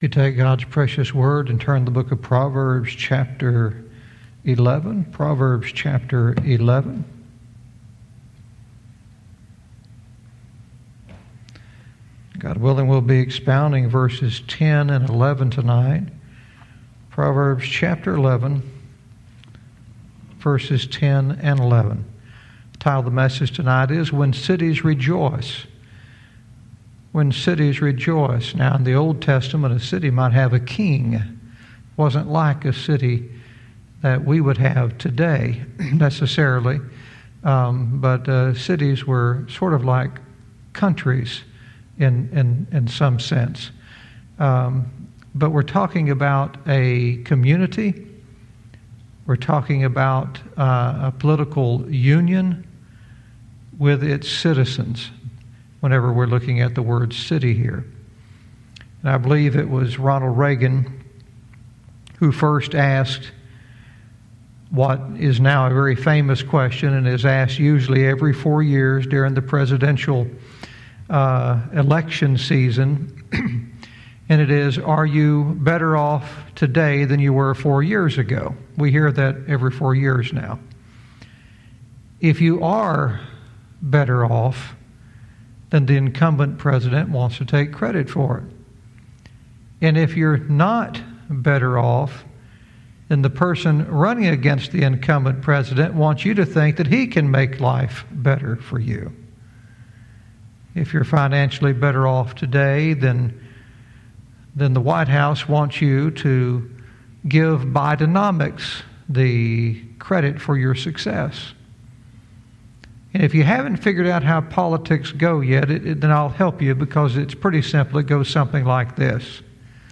Verse by verse teaching - Proverbs 11:10-11 "When Cities Rejoice"